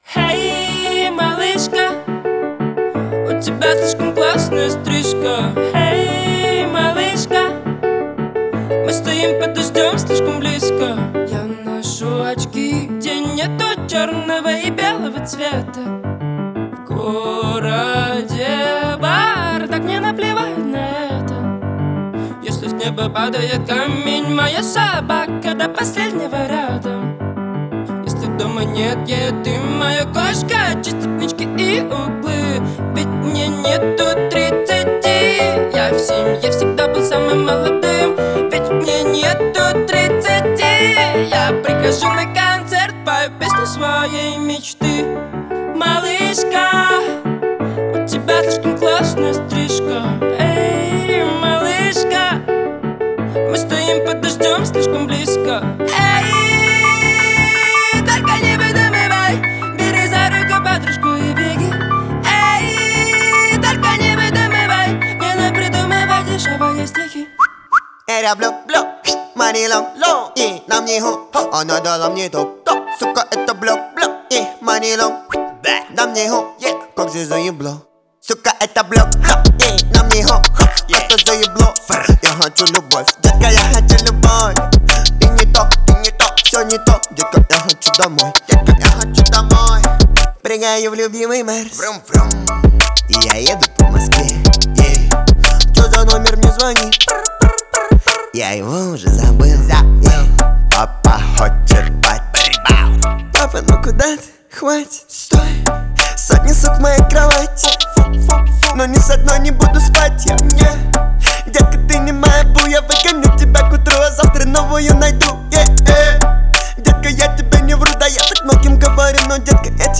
это яркая и энергичная композиция в жанре хип-хоп